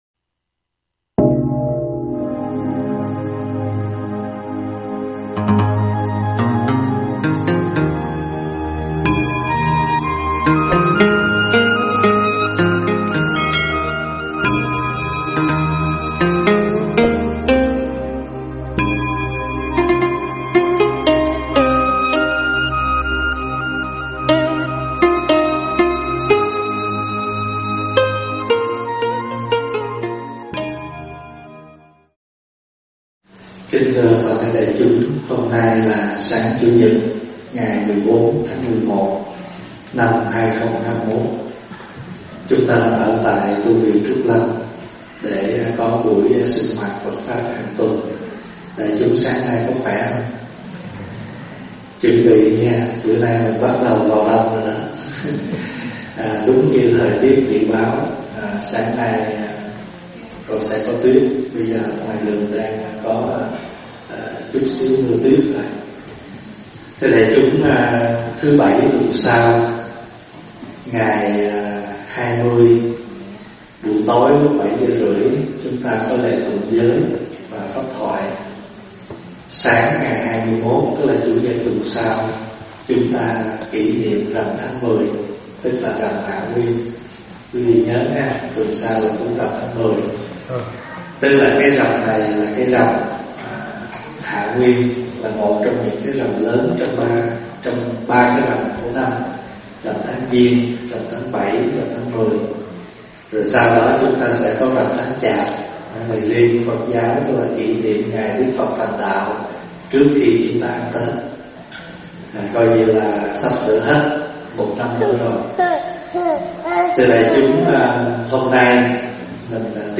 Thuyết pháp Lửa Nghiệp Ngấm Ngầm
giảng tại Tv.Trúc Lâm